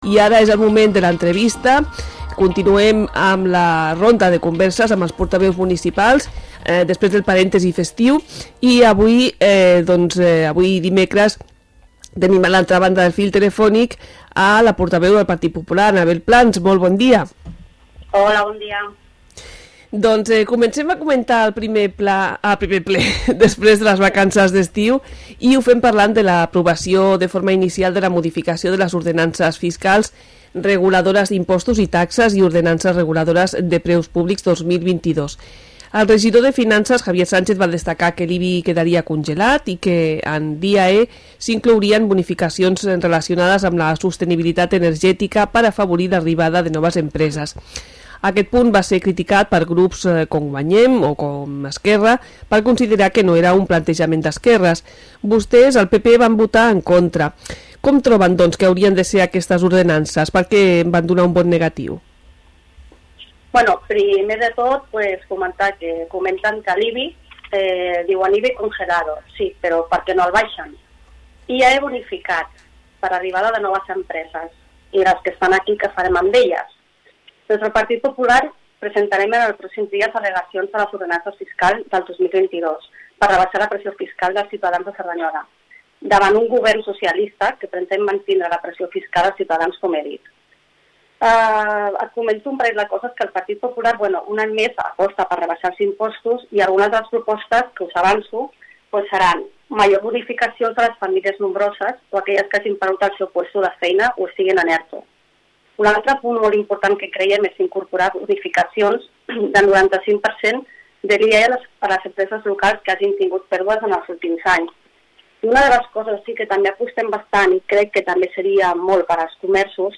Entrevista-Anabel-Plans-PP-Ple-setembre.mp3